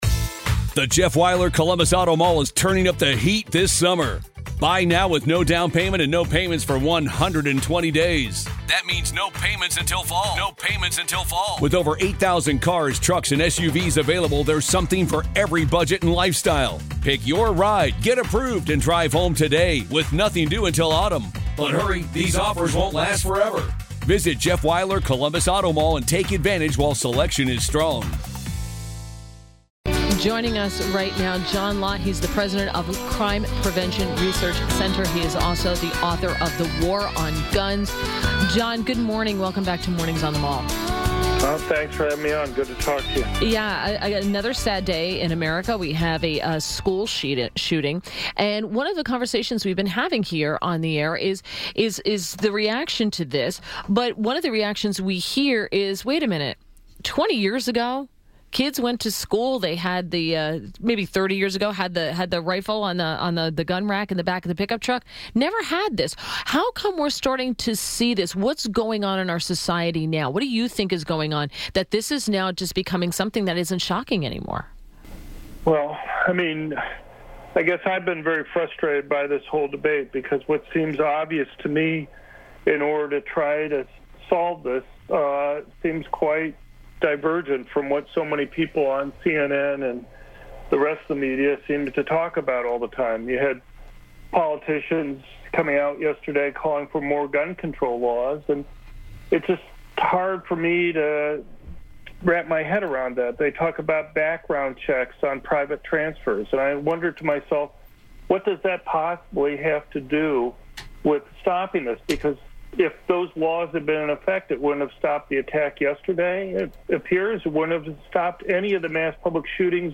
INTERVIEW - JOHN LOTT - President, Crime Prevention Research Center and author of "The War on Guns" – discussed the southern Florida school shooting and the implications it has politically